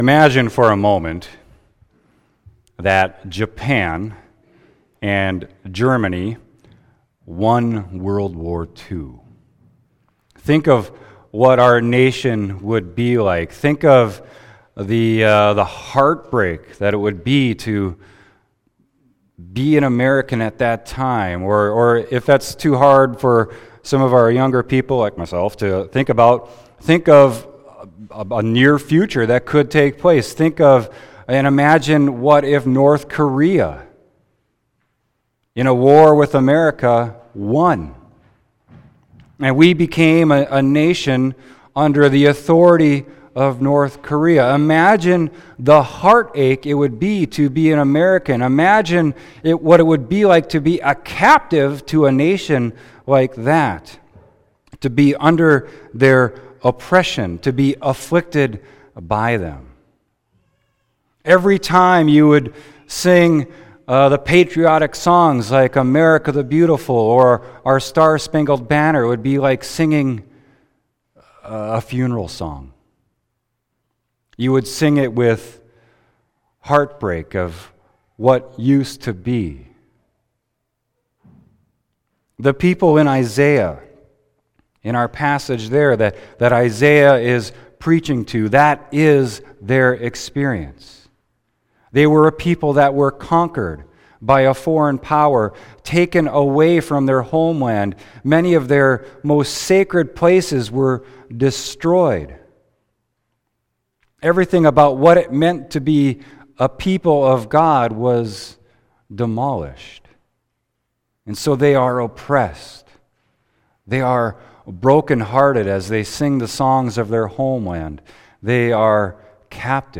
Scriptures: Isaiah 61:1-4, 8-11; Psalm 126; 1 Thessalonians 5:16-24; John 1:6-8, 19-28 Prayer of the Day: Lord, hear our prayers and come to us, bringing light into the darkness of our hearts; for you live and reign with the Father and the Holy Spirit, one God, now and forever, Amen. Sermon Isaiah 61.1-4, 8-11